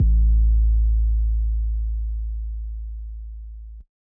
808 [Metro].wav